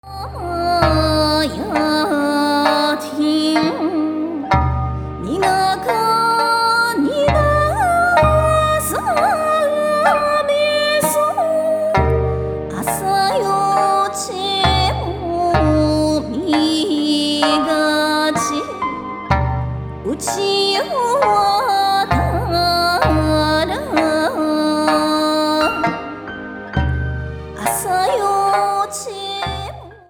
沖縄民謡、親の教えは爪を染めるように胸に染めてくださいね。